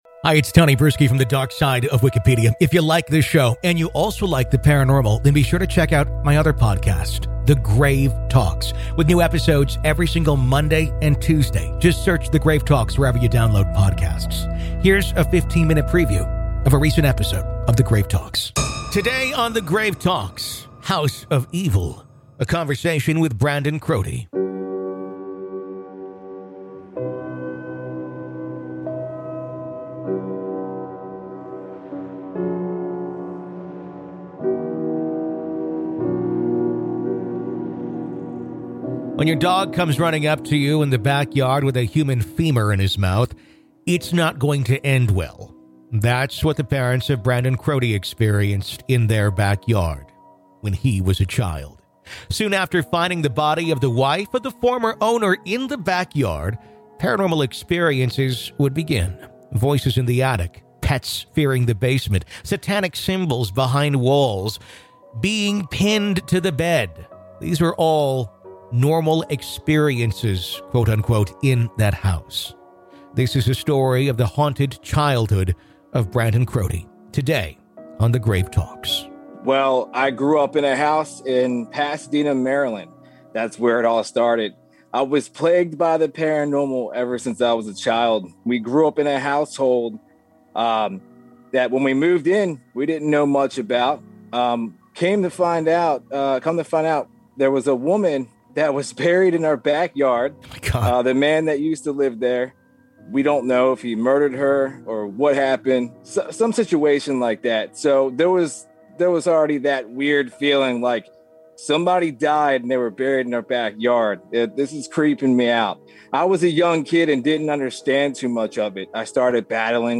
LISTEN HERE In part two of our interview